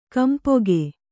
♪ kampoge